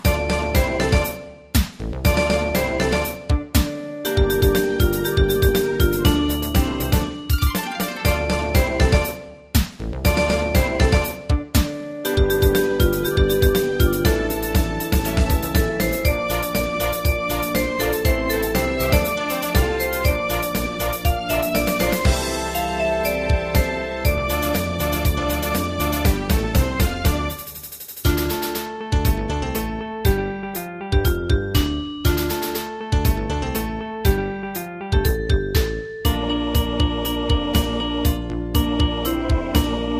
カテゴリー: アンサンブル（合奏） .